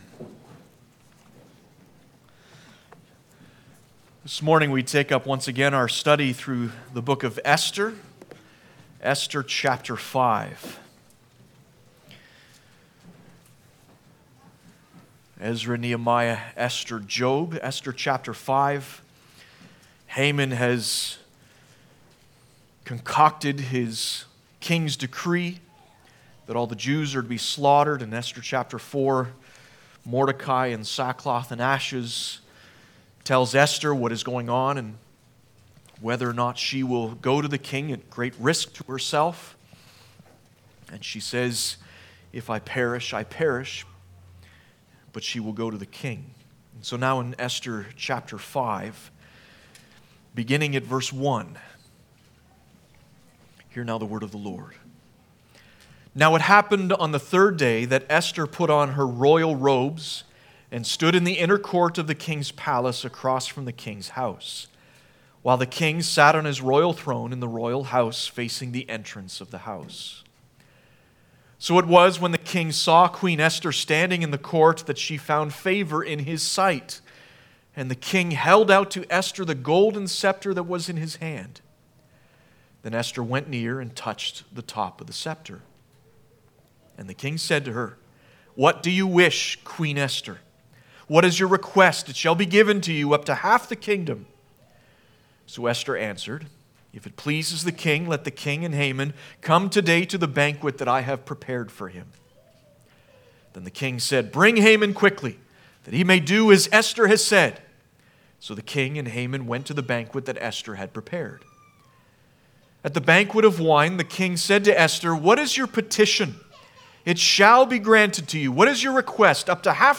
Esther 5 Service Type: Sunday Morning 1.